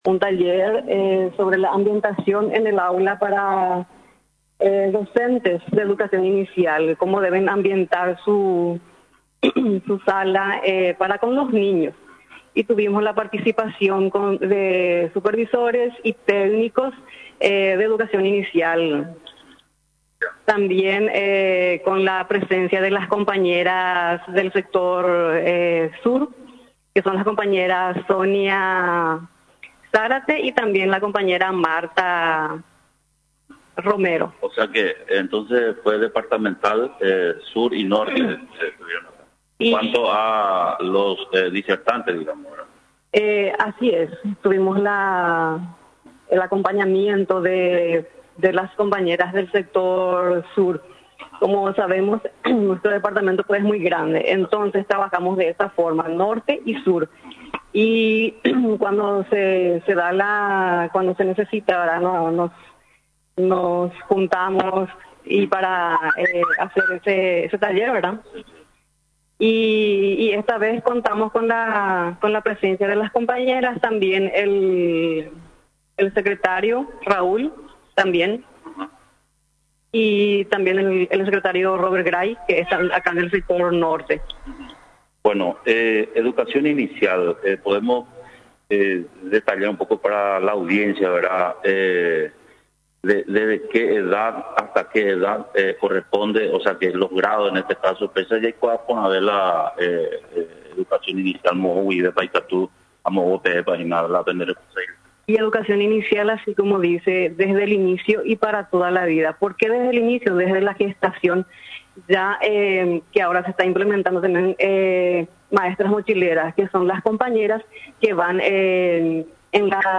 Los organizadores del evento, en conversación con nuestro medio, mencionaron de la importancia que tiene la ambientación pedagógica, entendida como la utilización de los recursos del aula, con el objetivo que el proceso de enseñanza y aprendizaje resulte más efectivo y significativos para los niños, ya que es fundamental un aula no solamente decorada, sino que ambientada para contribuir a mejorar la calidad de aprendizaje de los alumnos.